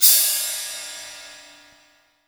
COIN CYMBAL.WAV